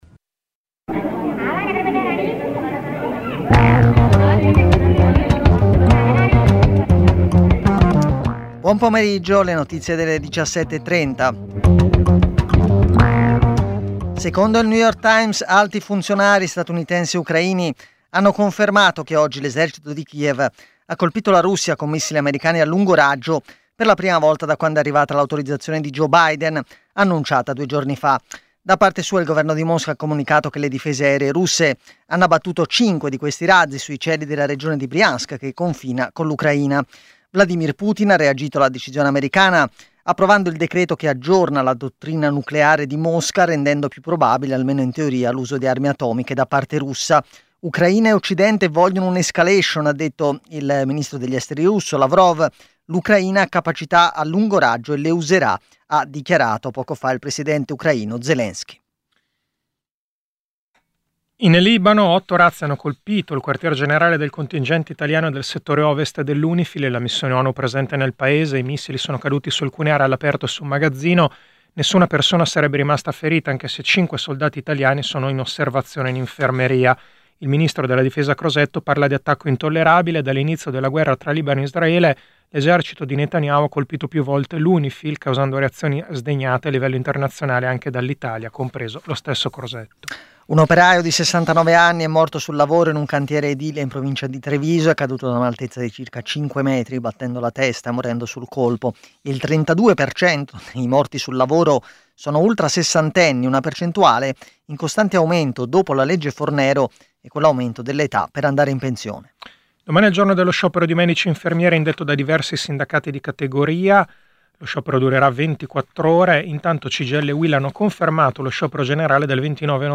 Giornale radio nazionale - del 19/11/2024 ore 17:30